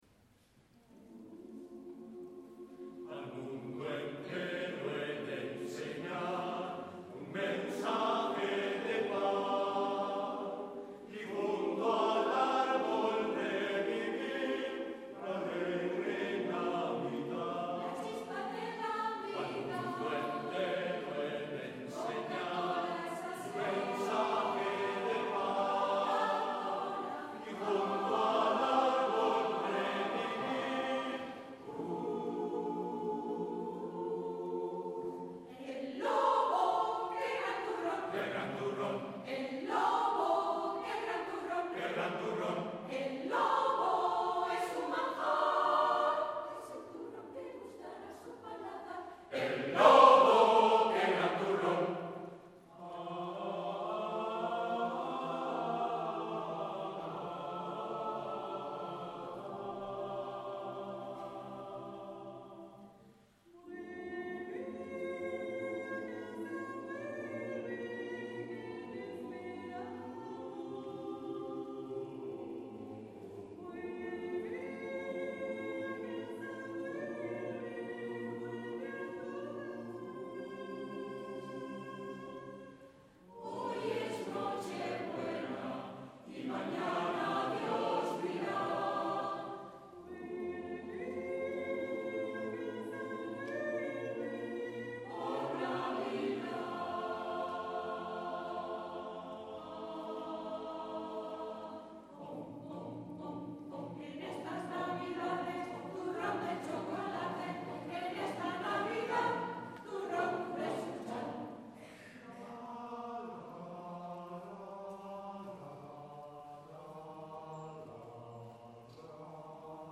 en concierto